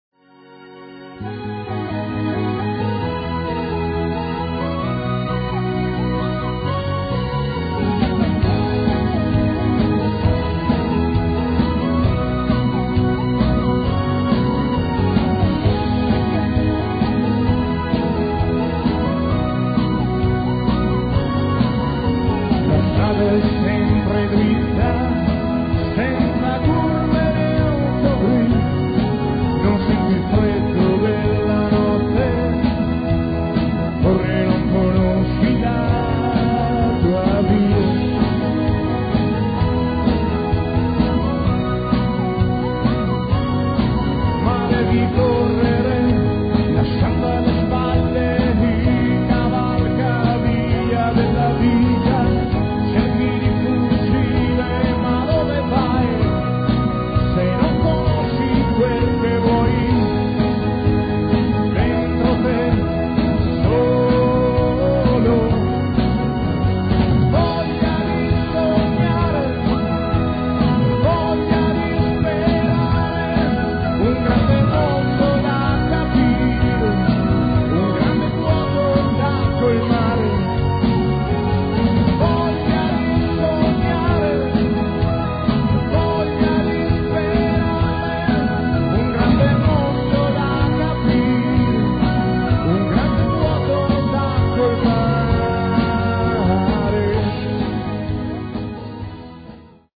alla chitarra e alla voce
alla batteria
al basso
alle tastiere